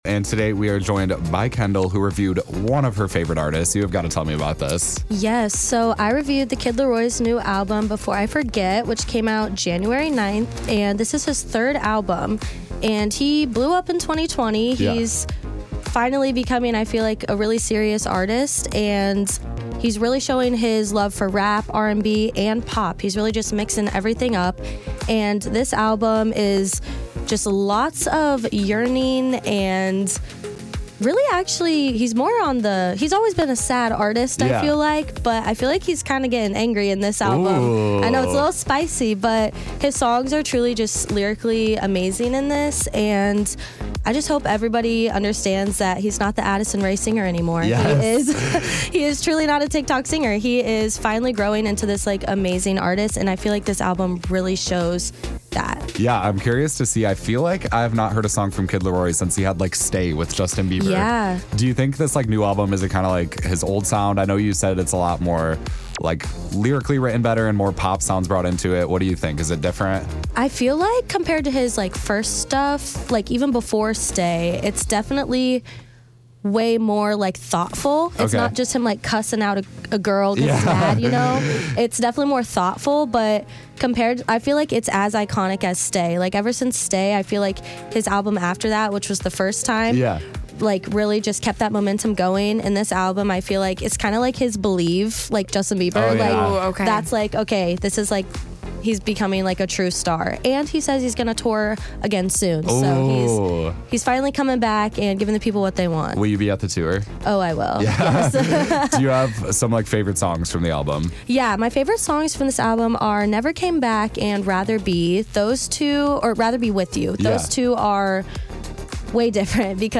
Before I Forget- The Kid Laroi Album Review